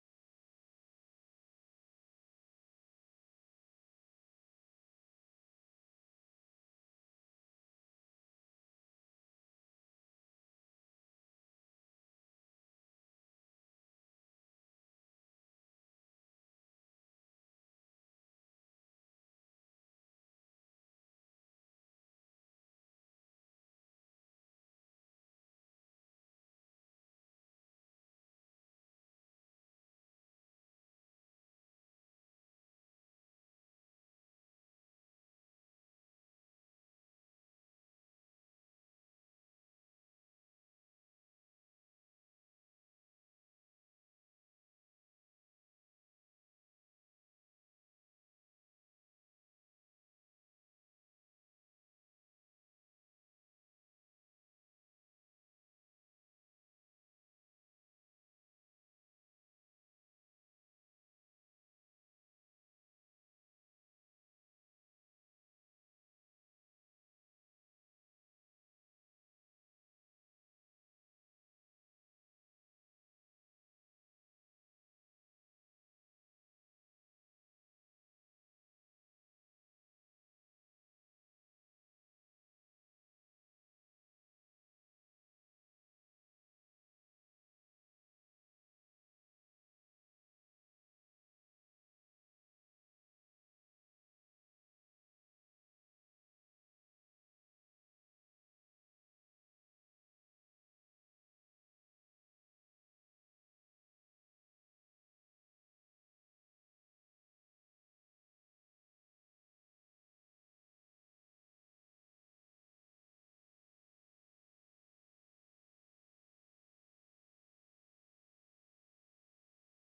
Sách nói | Đồi A1 Trận Địa Khốc Liệt Nhất Trong Chiến Thắng Điện Biên Phủ